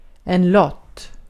Ääntäminen
IPA : /ˈpɹɒ.pə(ɹ).ti/